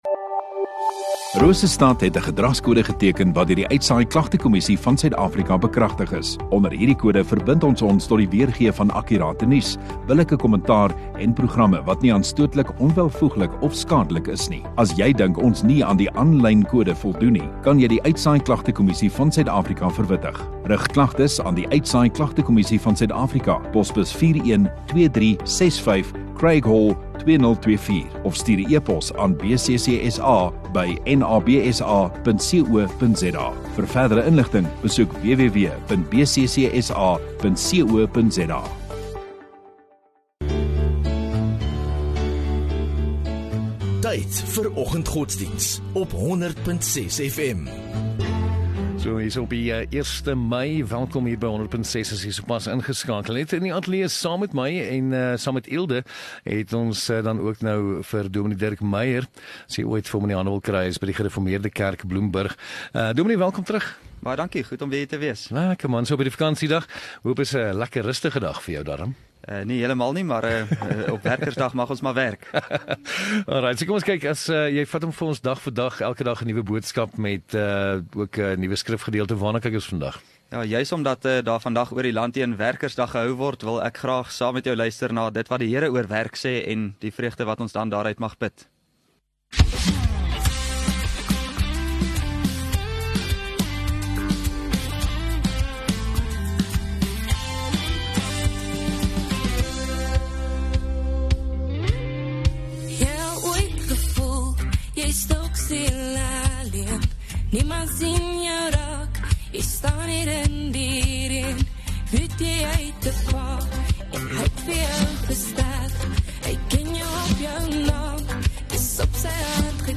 1 May Woensdag Oggenddiens